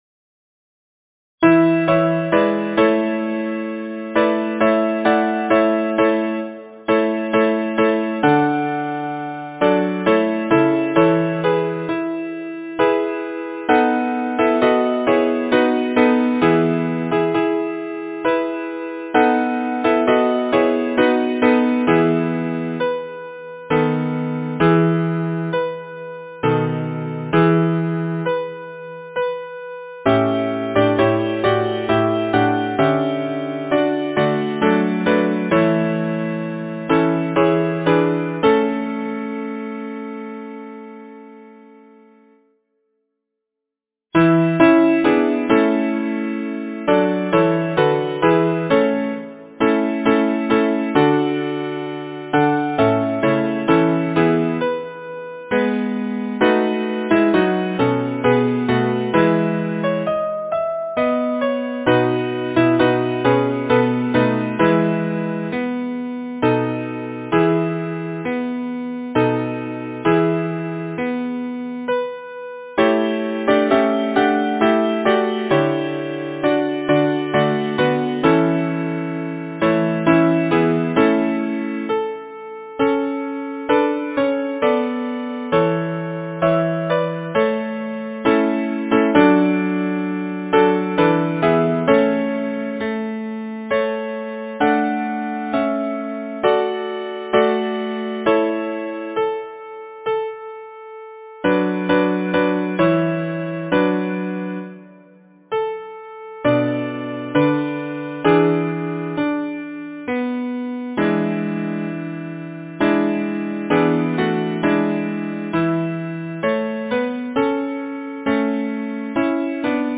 Title: Of all the Arts beneath the Heaven Composer: William Sterndale Bennett Lyricist: James Hogg Number of voices: 4vv Voicing: SATB Genre: Secular, Partsong
Language: English Instruments: A cappella